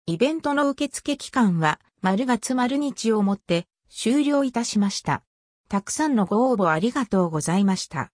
受付期間が終わったら自動応答が返答